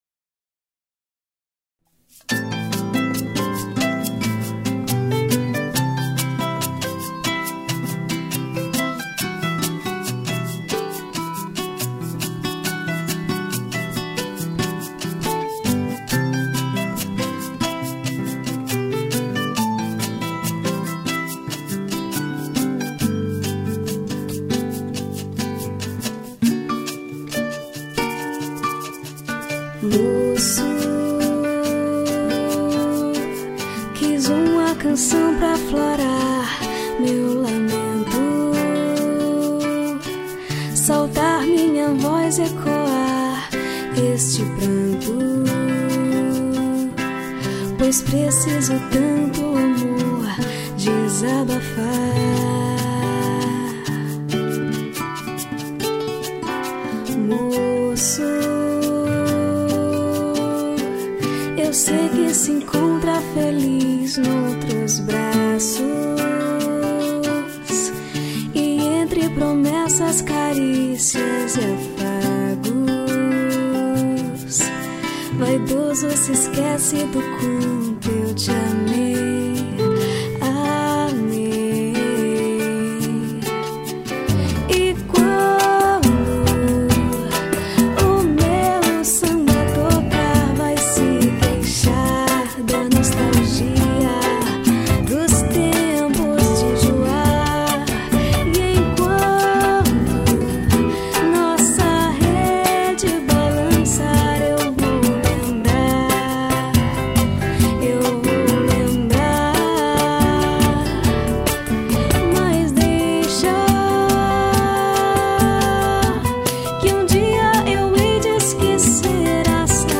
2820   03:15:00   Faixa: 10    Mpb